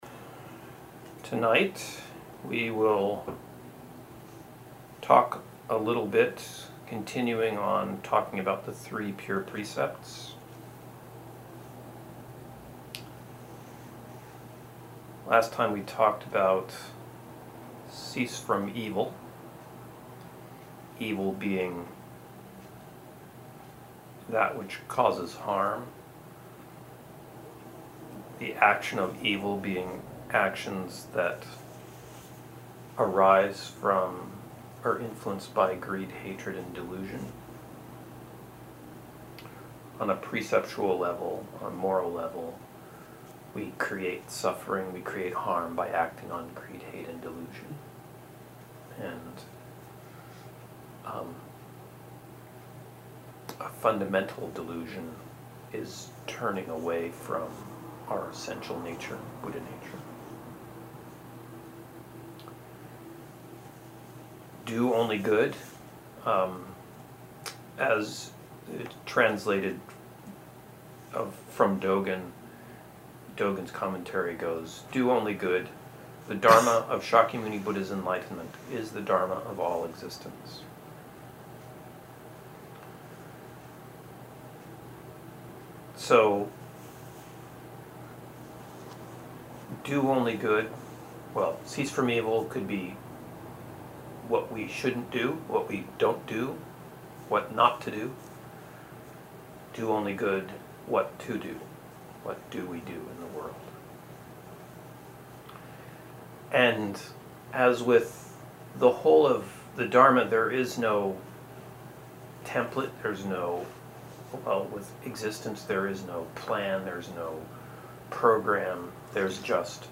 2018 The Second Pure Precept: Do Only Good. Link to talk: Listen / Download Date of talk: 2/14/18 Length of talk: 62 min/58 MB (the formal talk ends at 21 min.) Location of talk: Portland Priory